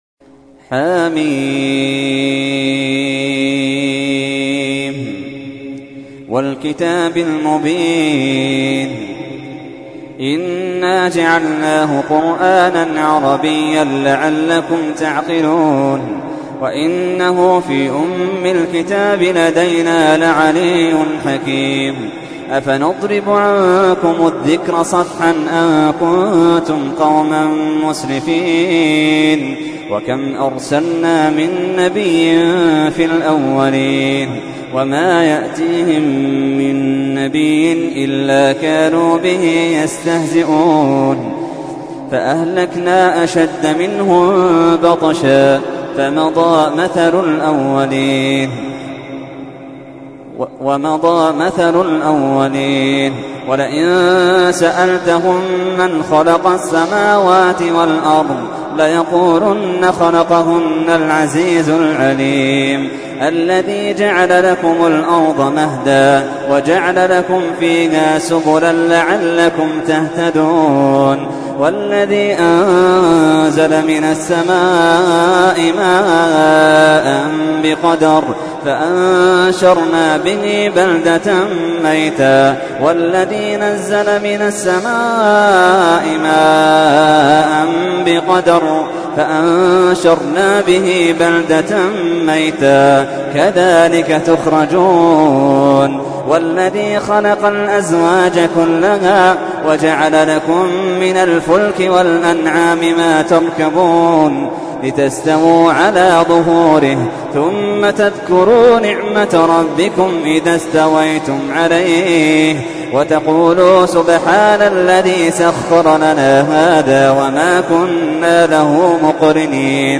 تحميل : 43. سورة الزخرف / القارئ محمد اللحيدان / القرآن الكريم / موقع يا حسين